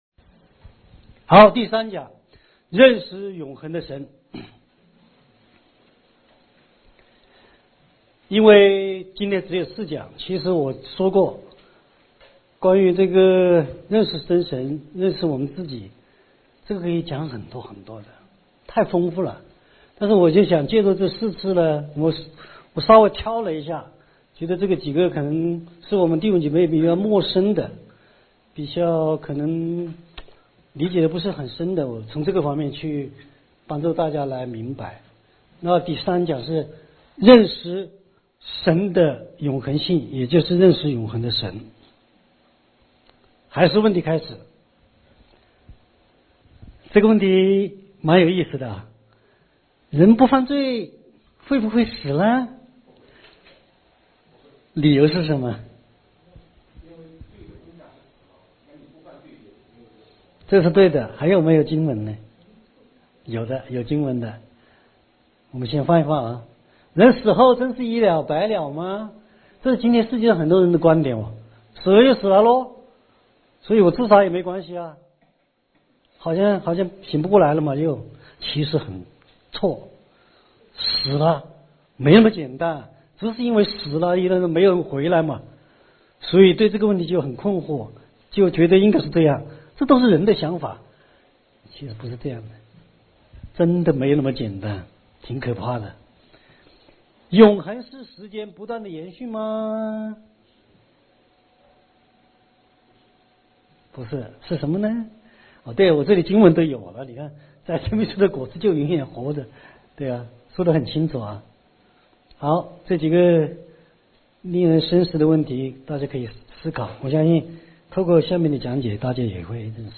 講座錄音 MP3 認識真神 認識自我 一 認識真神 認識自我 二 認識真神 認識自我 三 認識真神 認識自我 四 講座講義 認識真神 認識自我-講義 門徒訓練系列 – 認識真神 認識自我